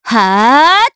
Xerah-Vox_Casting3_jp.wav